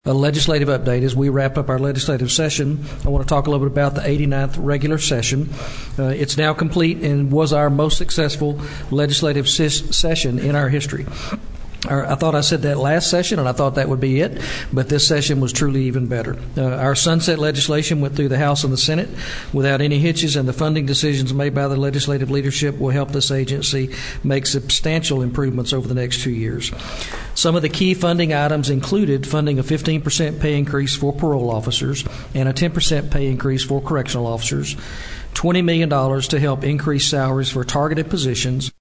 Click below to hear some of Bryan Collier’s comments from the June 26, 2025 Texas board of criminal justice meeting in Bryan: